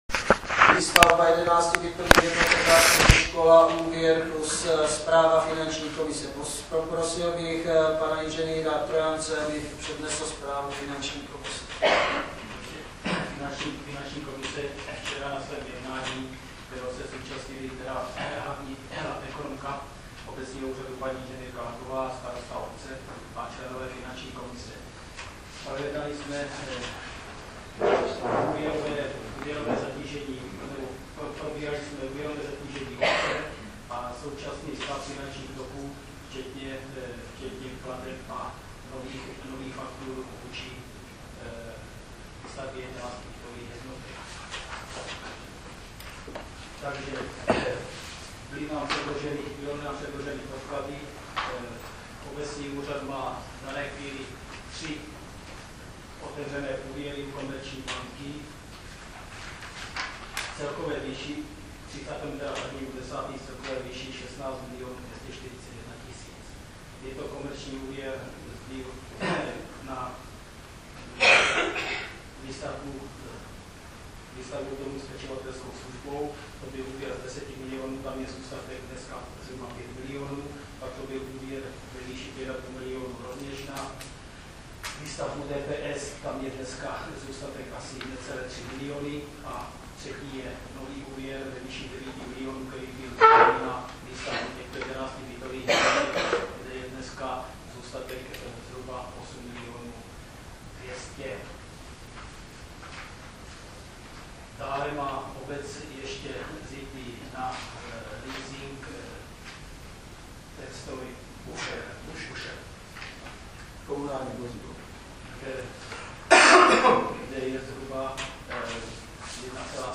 Však uslyšíte na zvukovém záznamu z tohoto zastupitelstva.
Na začátku mluví zástupce finanční komise, který není tolik slyšet, potom už je zvuk dobře slyšitelný.